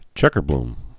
(chĕkər-blm)